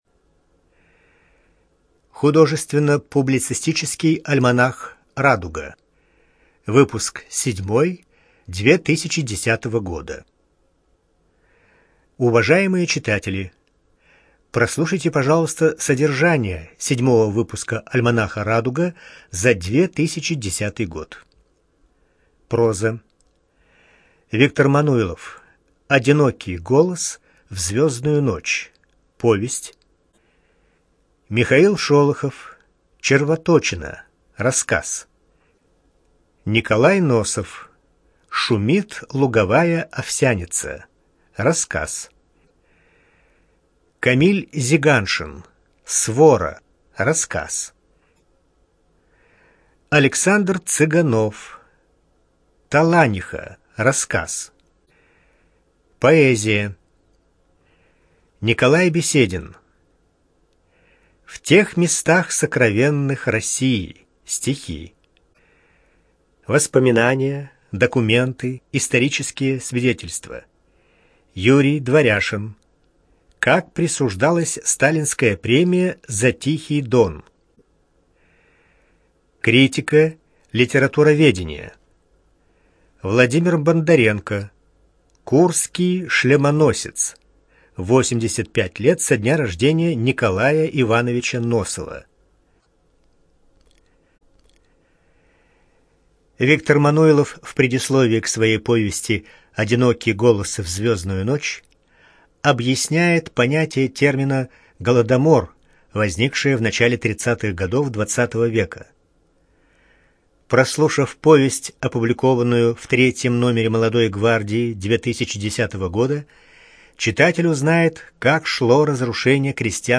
ЖанрСовременная проза, Публицистика, Поэзия
Студия звукозаписиЛогосвос